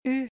“u” as in “déjà vu”
The trick is to pronounce an English “u” and then bring your tongue forward.